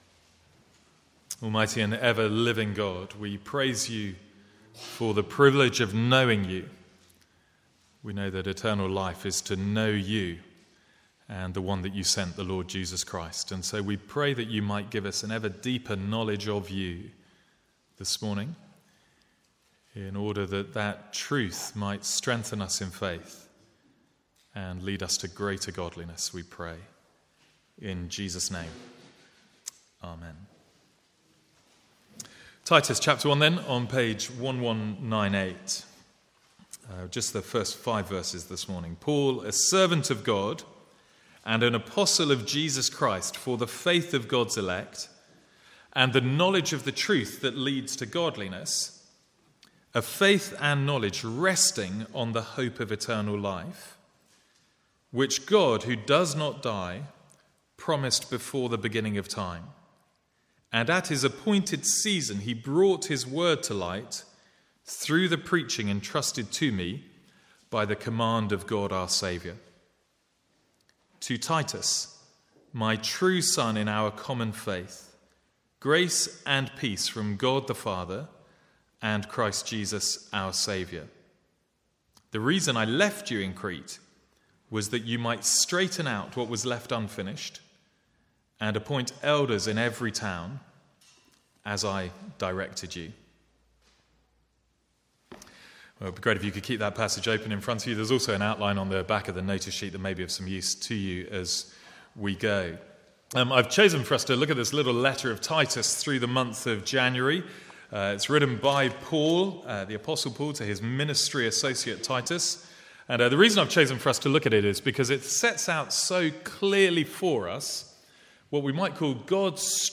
From the Sunday morning series in Titus.